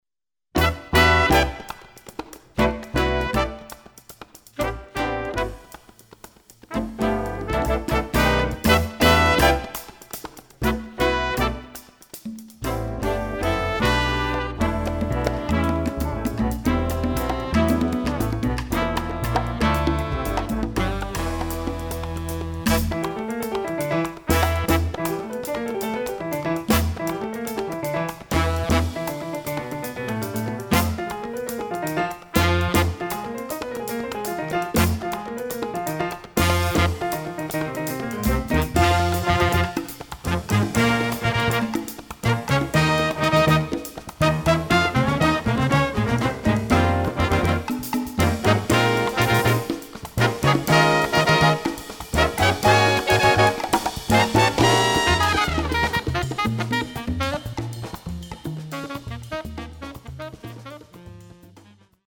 Category: combo (octet)
Style: mambo